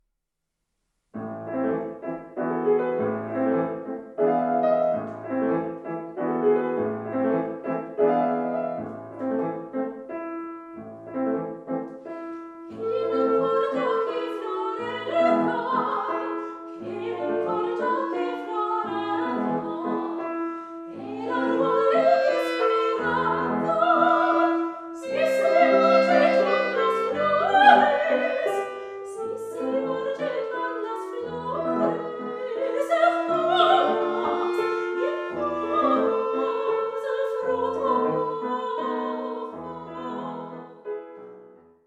Geburtstage etc. mit klassischer und moderner Musik.